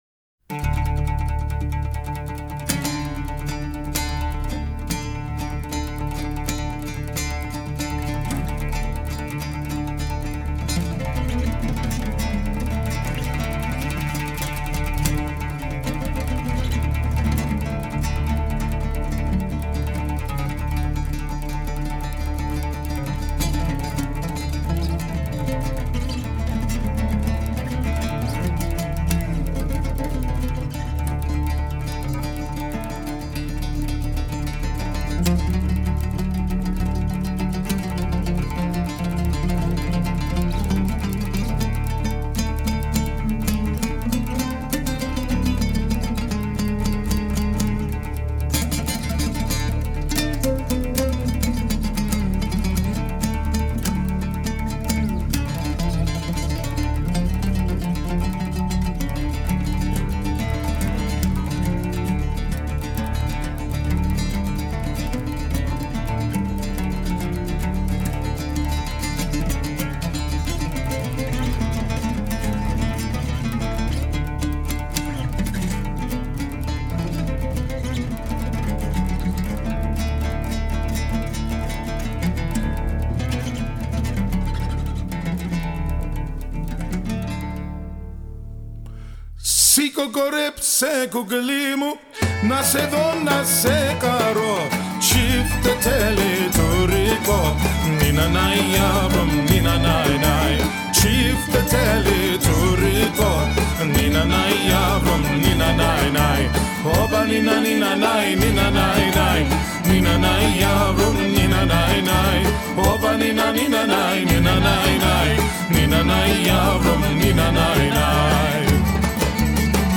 This Los Angeles Ethnic Musician is "America's Oud Virtuoso". He performs music and sings in 11 languages.
Sample Babacim Sample nina nai Los Angeles Ethnic Musician 1 This Los Angeles Ethnic Musician is "America's Oud Virtuoso".